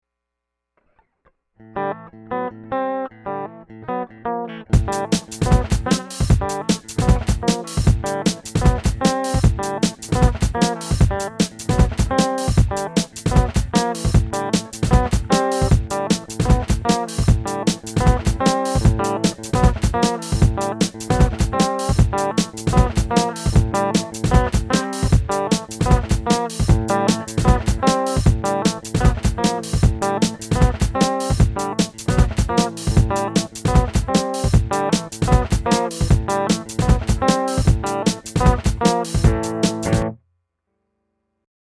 My "Road Song" with MY beat included.